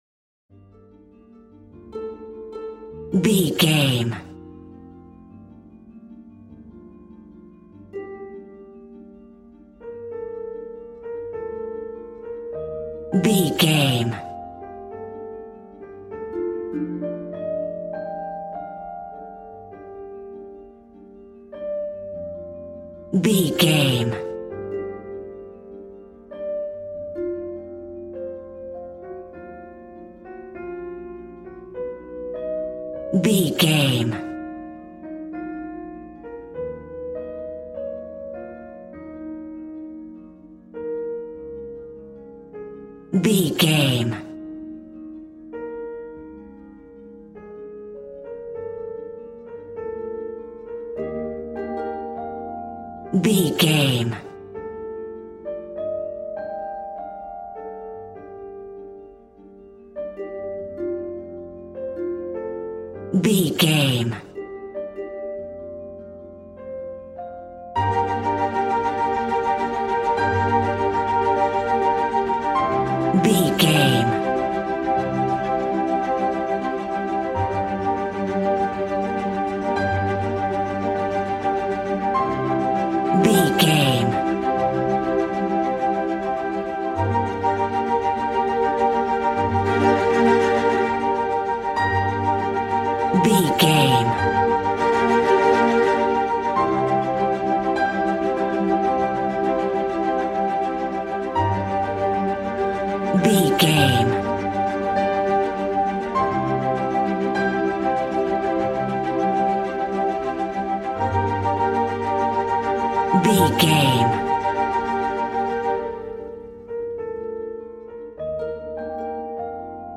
Regal and romantic, a classy piece of classical music.
Aeolian/Minor
strings
violin
brass